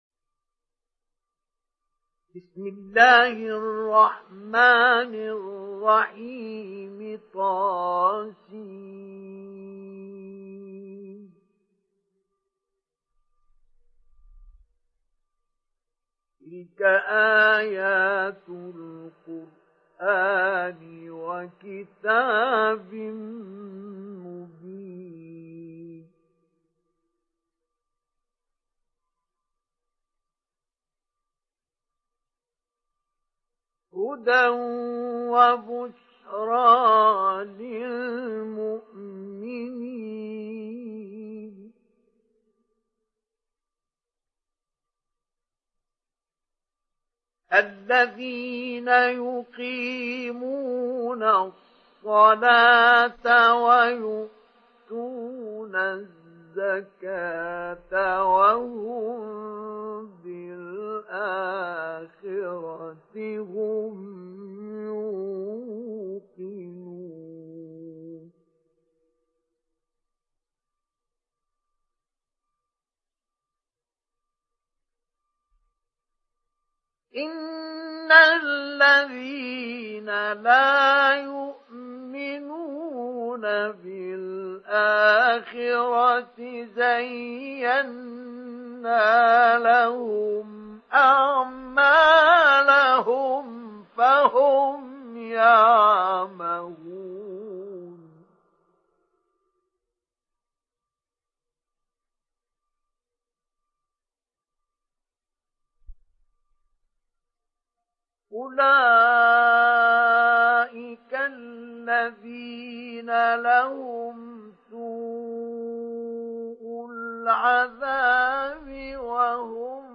Télécharger Sourate An Naml Mustafa Ismail Mujawwad